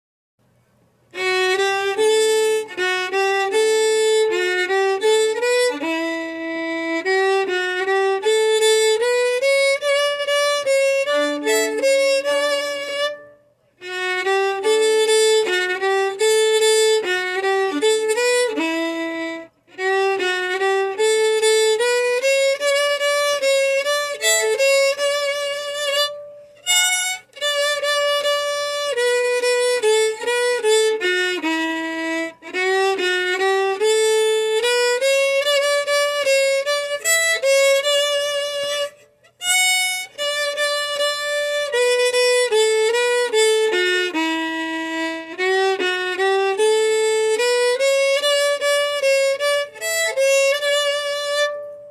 Key: D
Form: March (song)
Played slowly for learning
Region: USA (South)